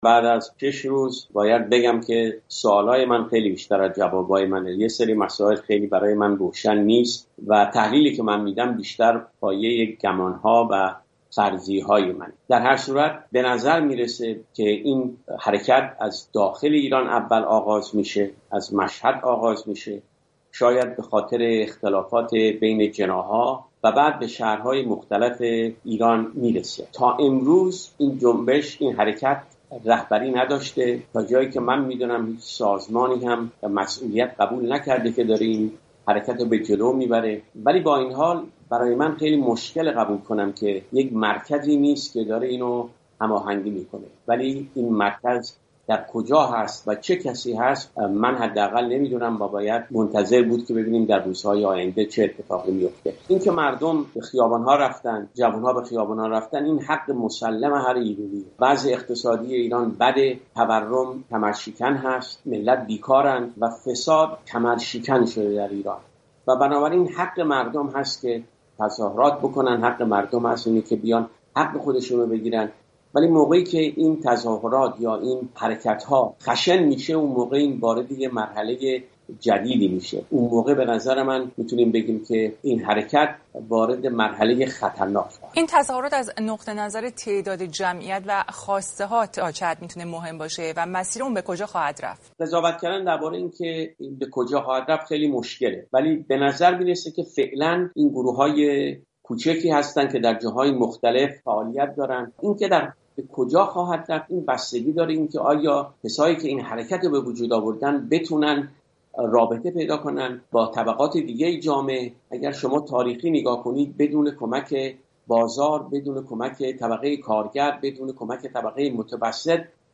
تحلیلگر سیاسی مقیم آمریکا